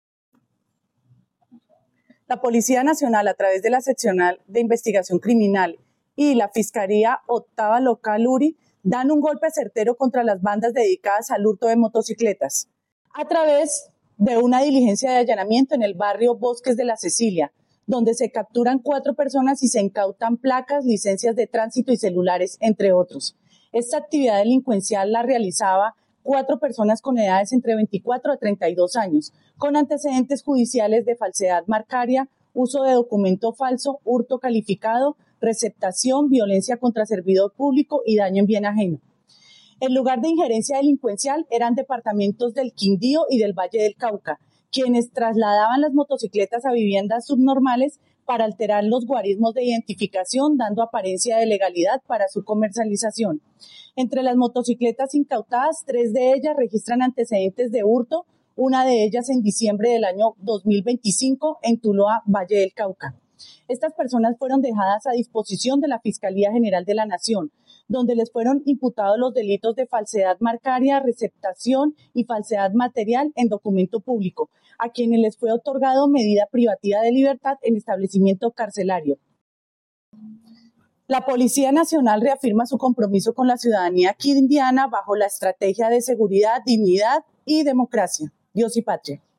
Teniente Coronel, Claudia Marcela Cañas Peña, comandante de la Policía Quindío